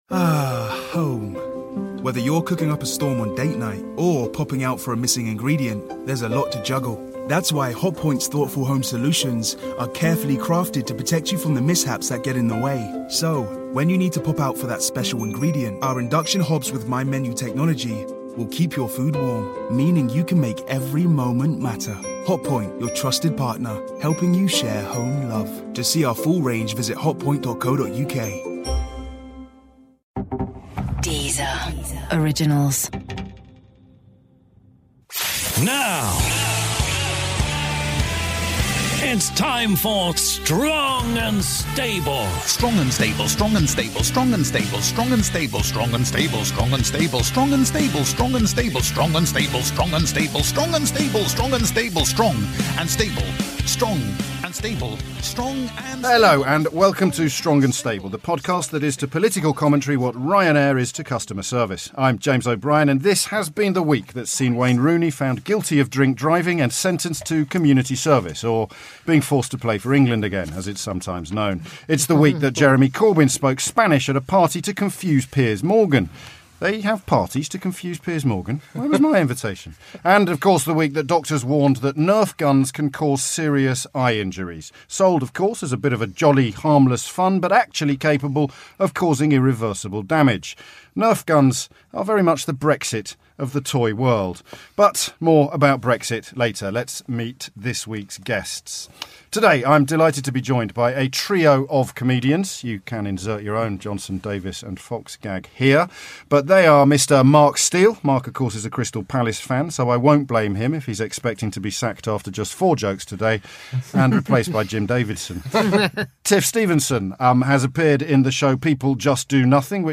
With guests This week on Strong & Stable our host James O'Brien is joined in the studio by comedians Mark Steel, Tiff Stevenson and Josh Widdicombe. Plus, we have an exclusive extract from the PM's Florence speech, and we hear from roving reporter Jonathan Pie.